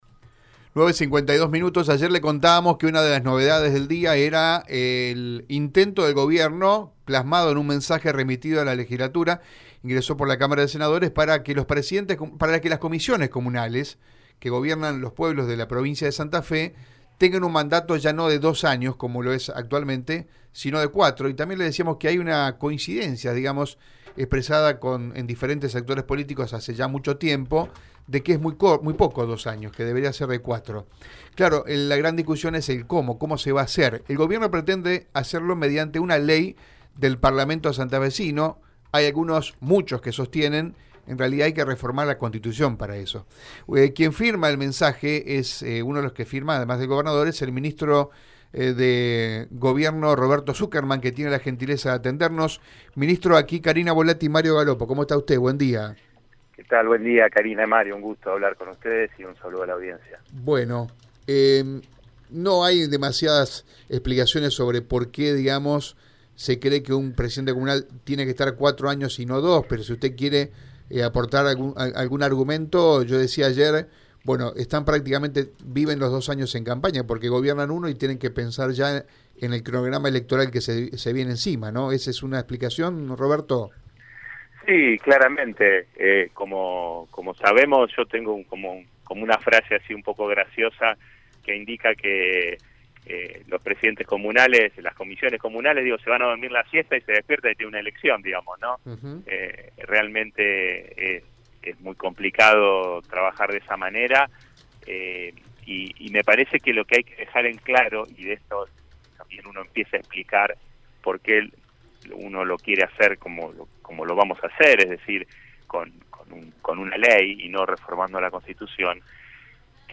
Así lo sostuvo el ministro de Trabajo de Santa Fe, Roberto Sukerman, en Radio EME.